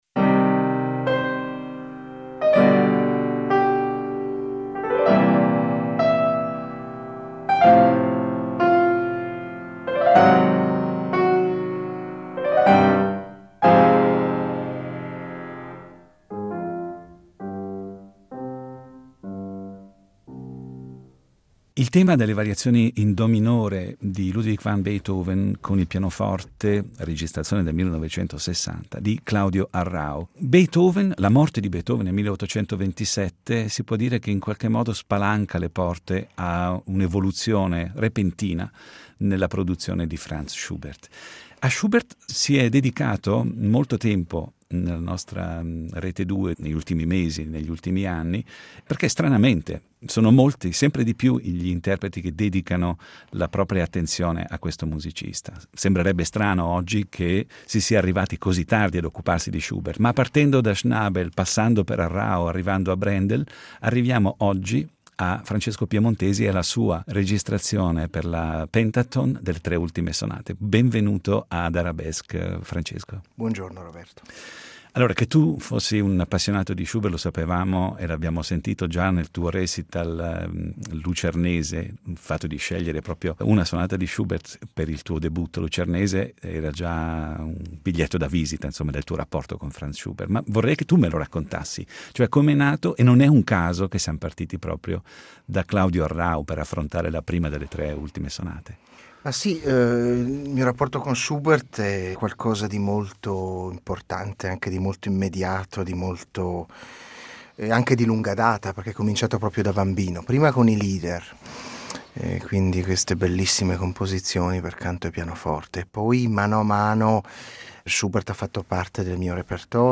Incontro con Francesco Piemontesi
il pianista Francesco Piemontesi